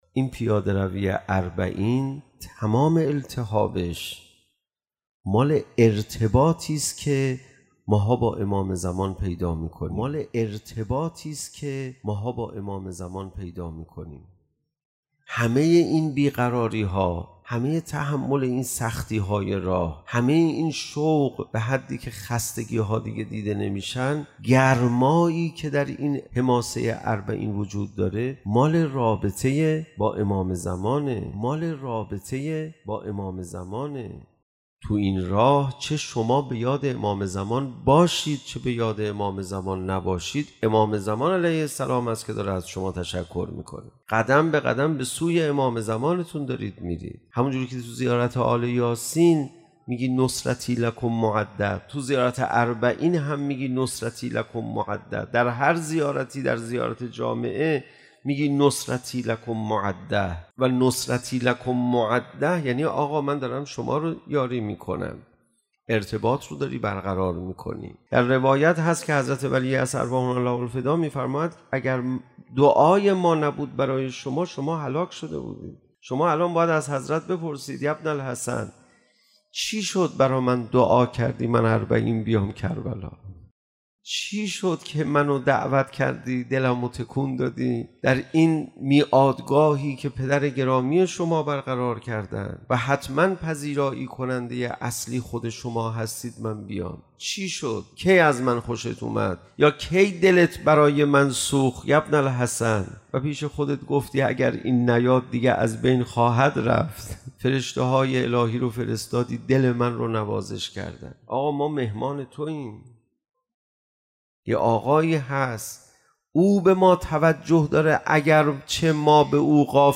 منبع: مسجد سهله، موکب مع الإمام‌المنصور